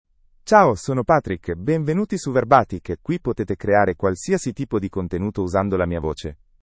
MaleItalian (Italy)
PatrickMale Italian AI voice
Patrick is a male AI voice for Italian (Italy).
Voice sample
Male